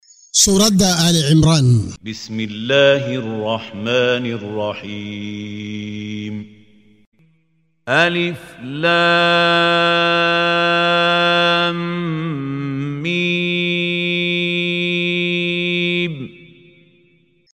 Waa Akhrin Codeed Af Soomaali ah ee Macaanida Suuradda Suuradda Aal-Cimraan ( Reer Cimraan ) oo u kala Qaybsan Aayado ahaan ayna la Socoto Akhrinta Qaariga Sheekh Maxmuud Khaliil Al-Xusari.